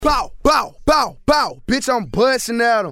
Tm8_Chant4.wav